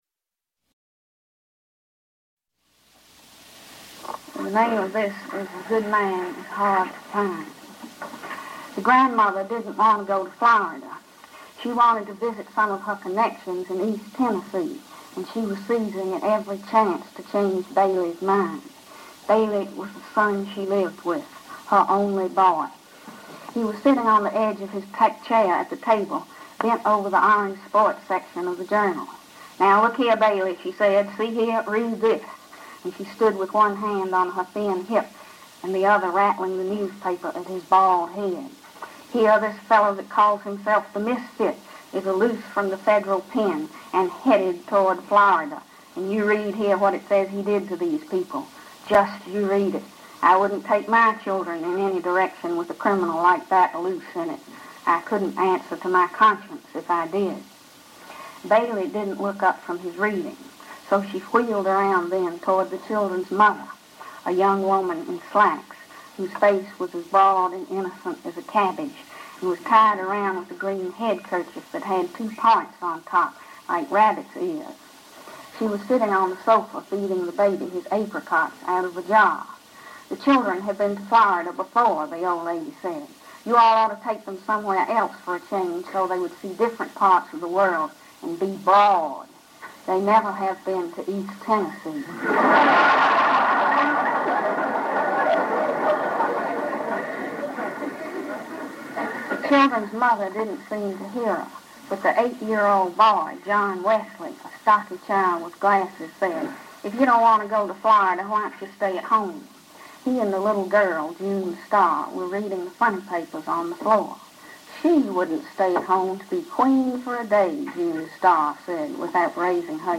This is a recording from 1959 of Flannery O’Connor herself reading A Good Man is Hard to Find at Vanderbilt University.